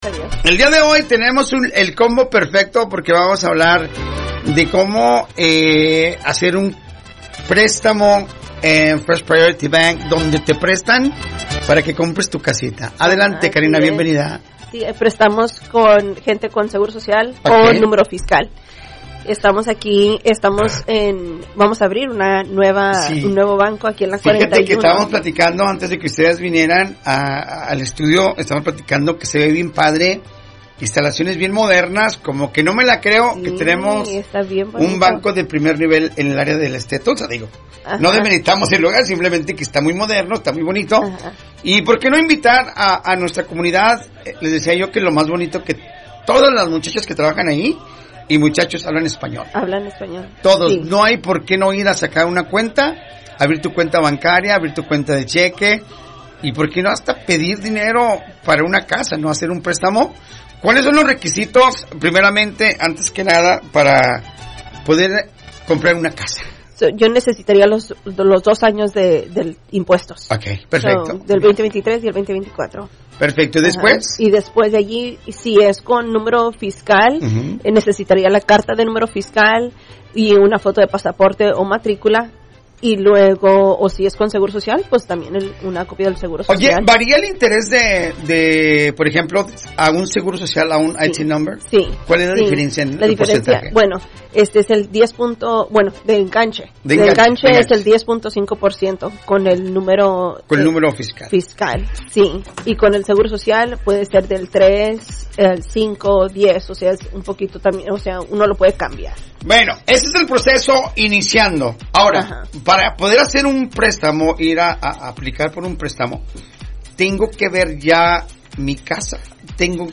Entrevista-FirstPryorityBank-16Septiembre25.mp3